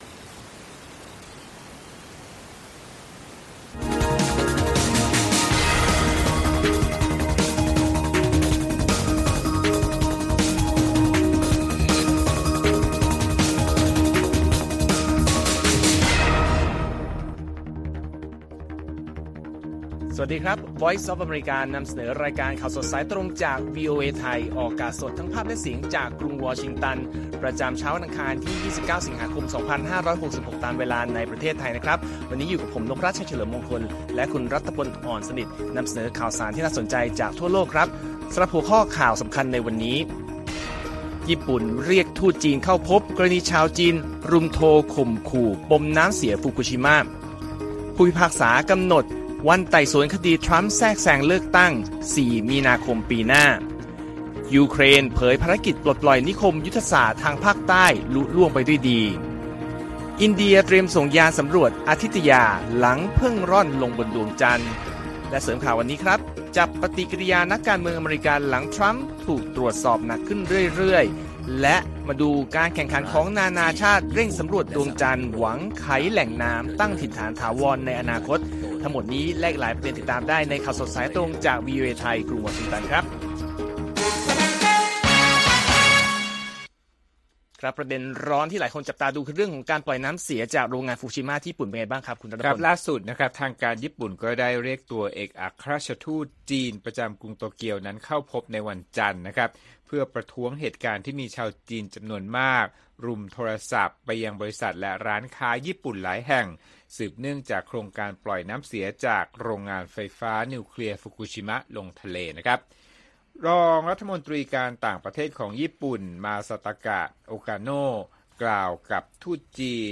ข่าวสดสายตรงจากวีโอเอ ไทย อังคาร 29 สิงหาคม 2566